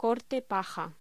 Locución: Corte paja
voz